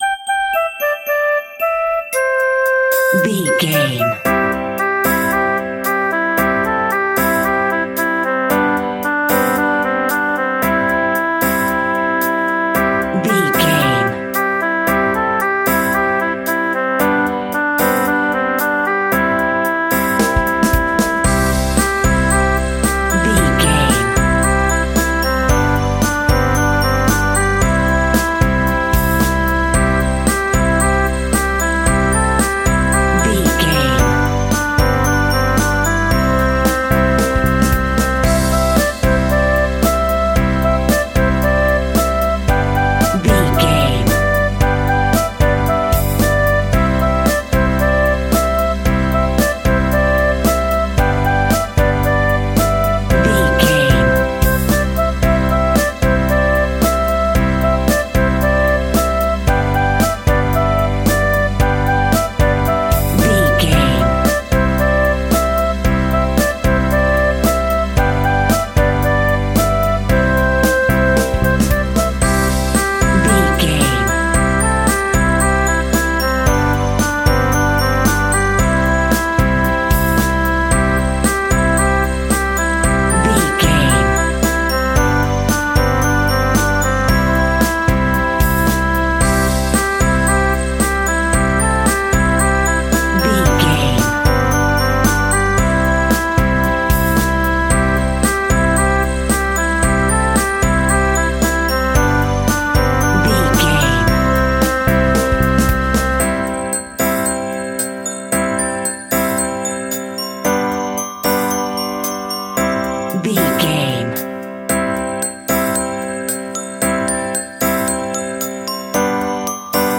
Kids are Happy.
Ionian/Major
instrumentals
childlike
cute
happy
kids piano